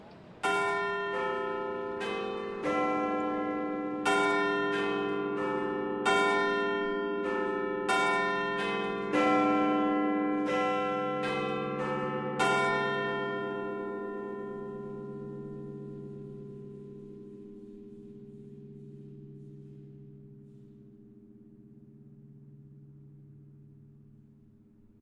chimes.ogg